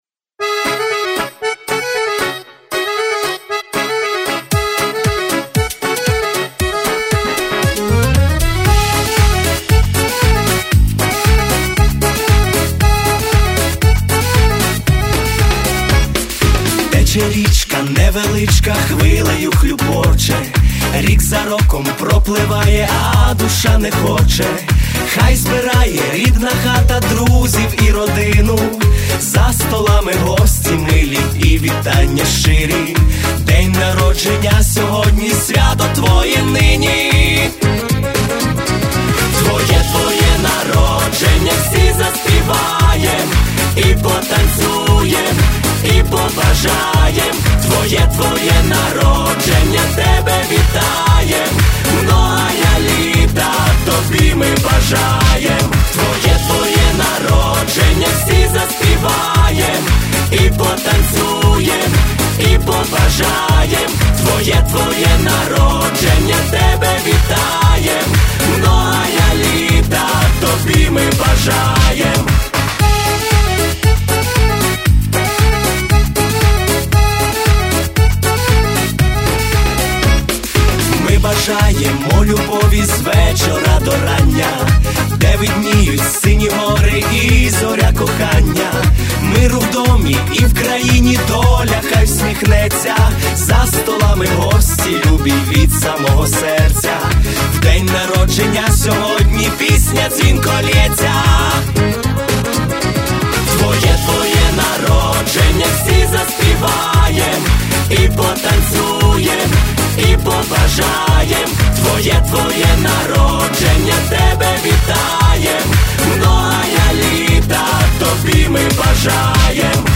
Всі мінусовки жанру Pop-UA
Плюсовий запис
Просто супер, а які голоси і подача!